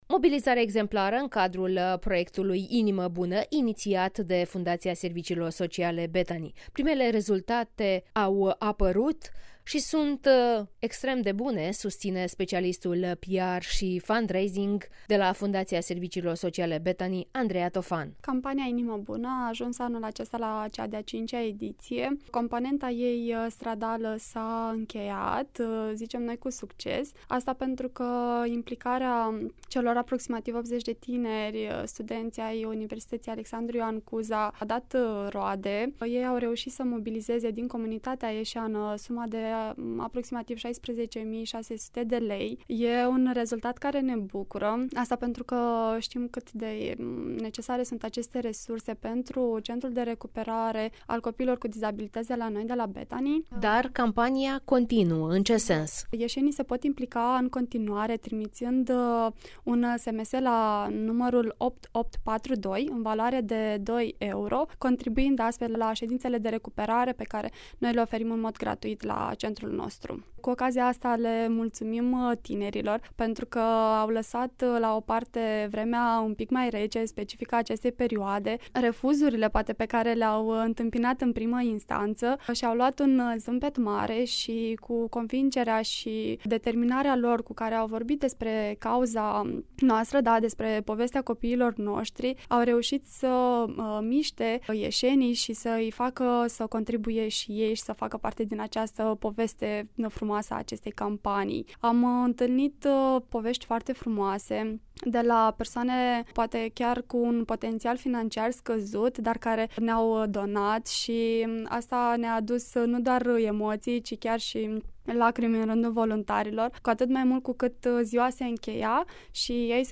(INTERVIU) Campania Inimă Bună se derulează cu succes